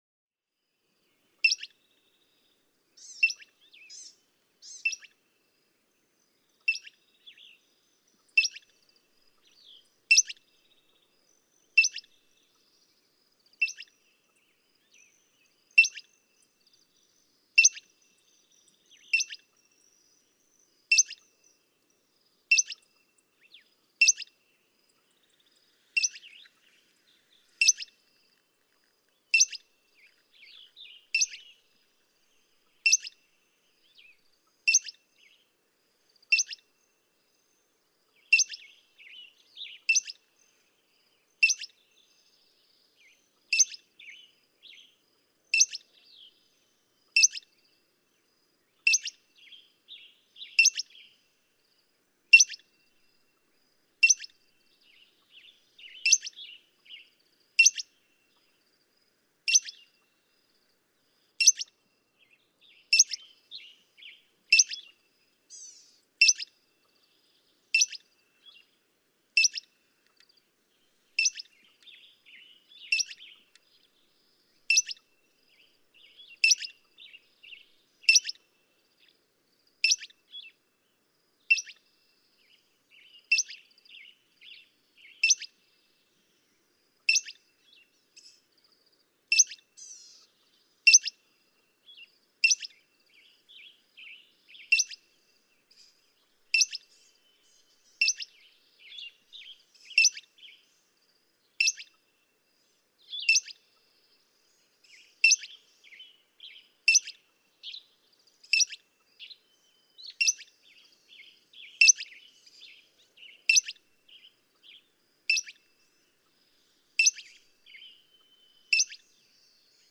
Metronome—loggerhead shrike
Like a metronome, the loggerhead shrike delivers his simple songs. Western tanager singing in background.
Temple Canyon Park, Cañon City, Colorado.
657_Loggerhead_Shrike.mp3